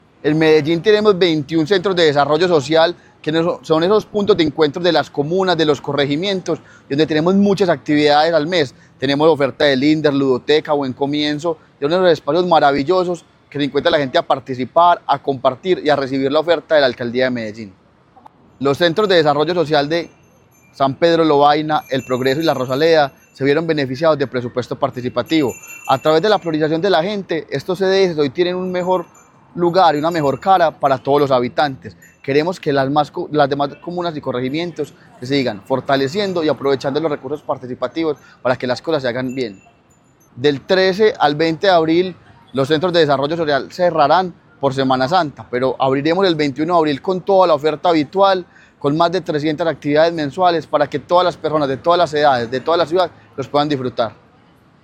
Palabras de Camilo Cano Montoya, secretario de Participación Ciudadana